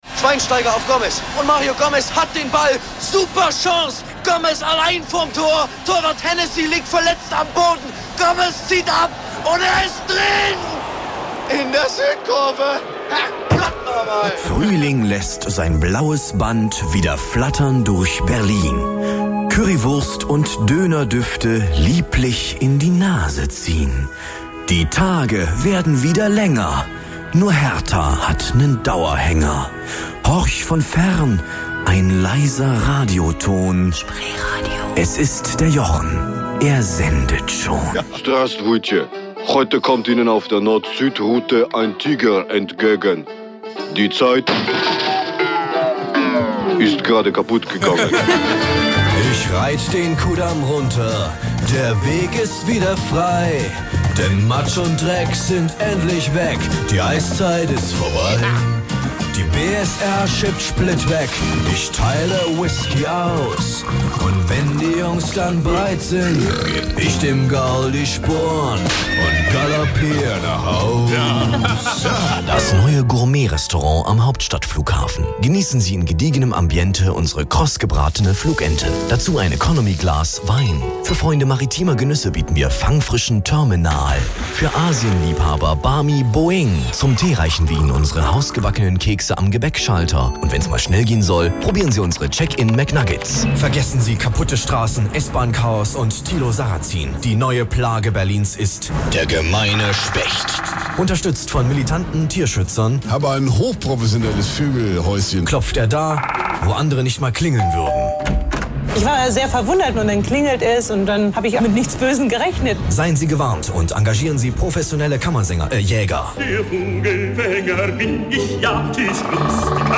Demo Werbespot 2 (mit Dialekt)